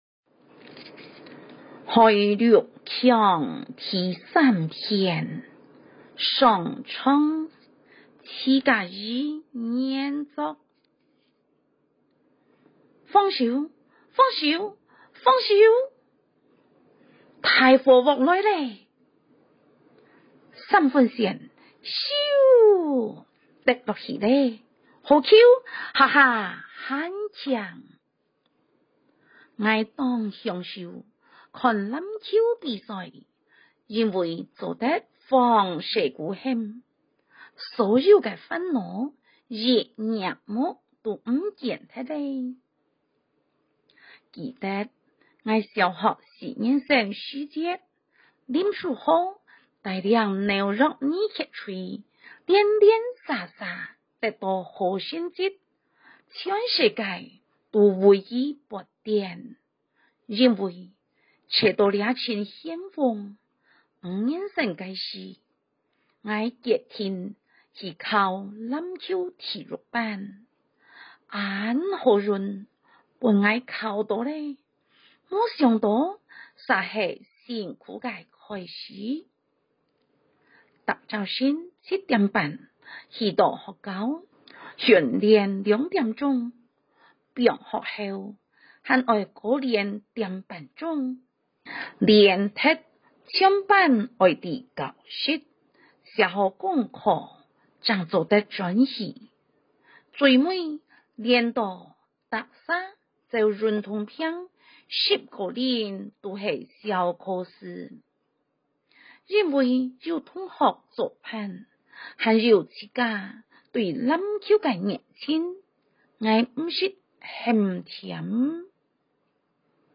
高峰國小113學年度校內語文競賽實施計畫、國英閩客朗讀文稿、閩客語朗讀音檔